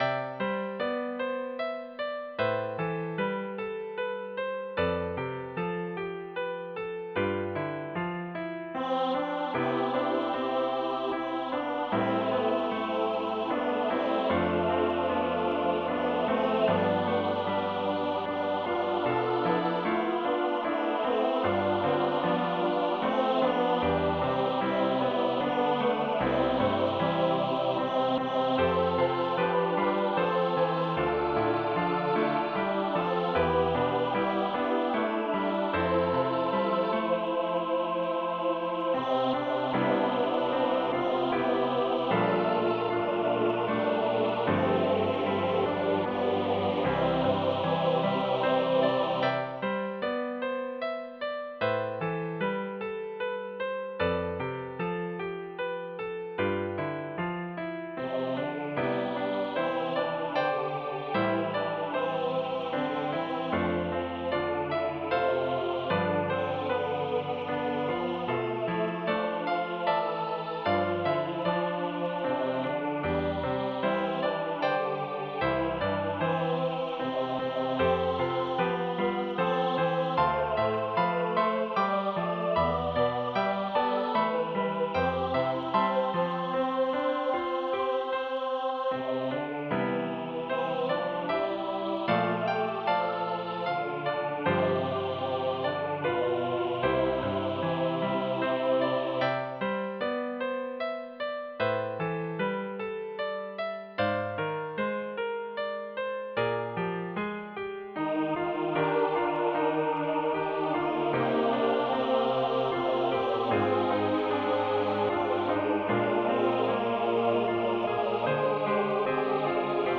An original Christmas carol for SATB choir and piano.